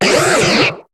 Cri de Venalgue dans Pokémon HOME.